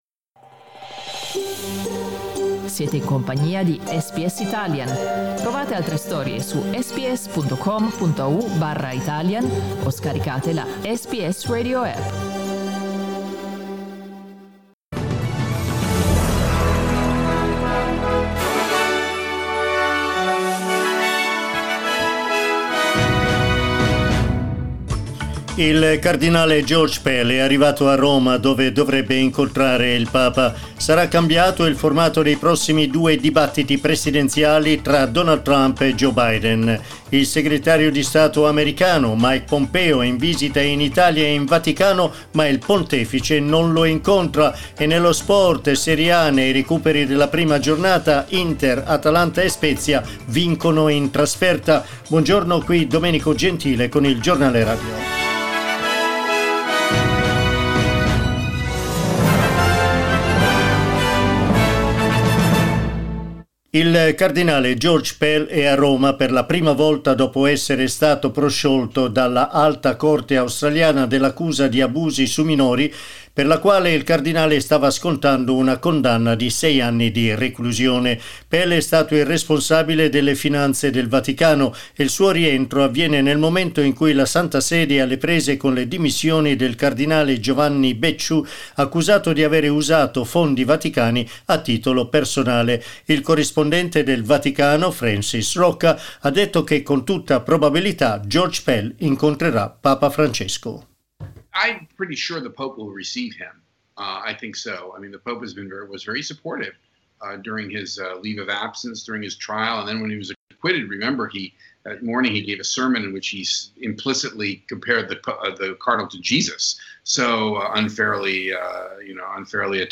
News bulletin in Italian broadcast this morning at 09:00am.